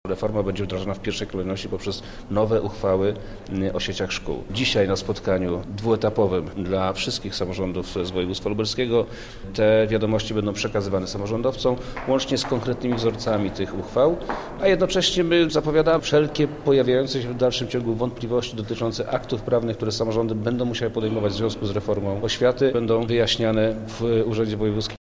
– Wprowadzanie reformy wiąże się z wieloma zmianami w prawie lokalnym oraz organizacji działania samorządów – mówi Wojewoda Lubelski, Przemysław Czarnek: